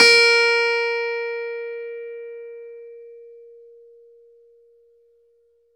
CLV_ClavDBA#5 2b.wav